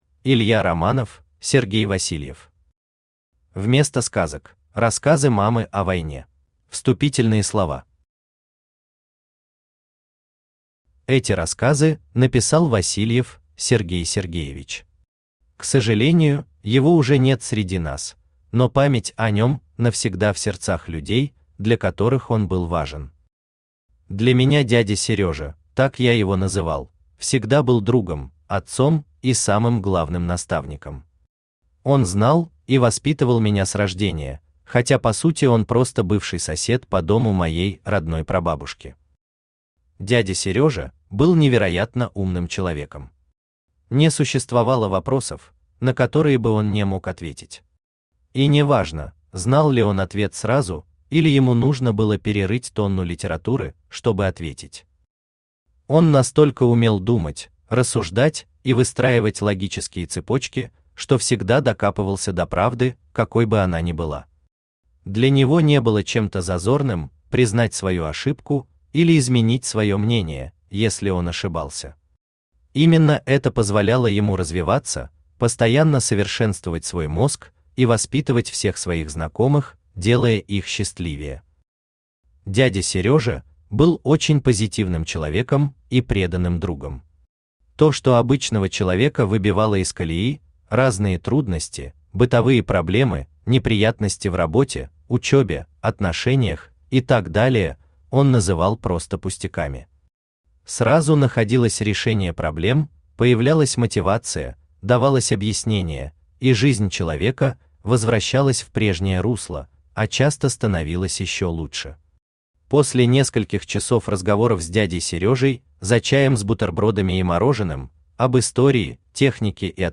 Aудиокнига Вместо сказок: рассказы мамы о войне Автор Илья Романов Читает аудиокнигу Авточтец ЛитРес.